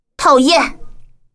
女孩说讨厌音效免费音频素材下载